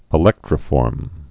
(ĭ-lĕktrə-fôrm)